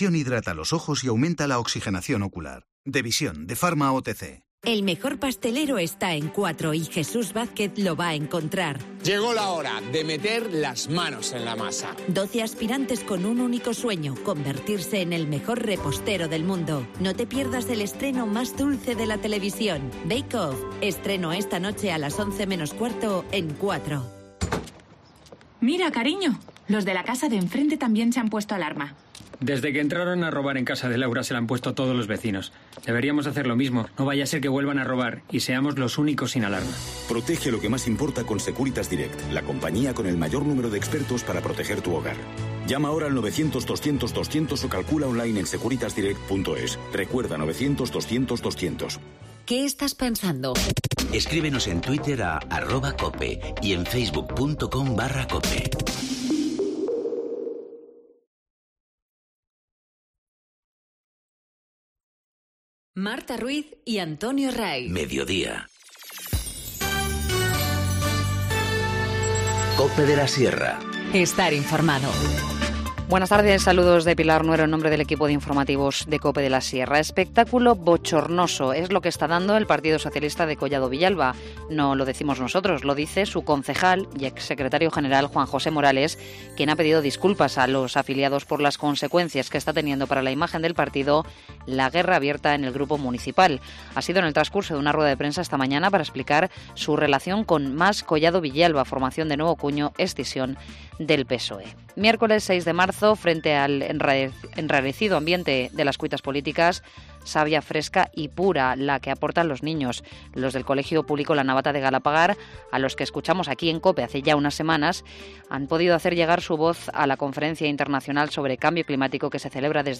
Informativo Mediodía 6 marzo 14:20h
-Juan José Morales, concejal socialista en Collado Villalba, explica su relación con otro partido político -Pedro Sánchez lee una carta de los alumnos de La Navata en la Conferencia Internacional sobre el Cambio Climático -Ampliación del Centro de Salud de Collado Mediano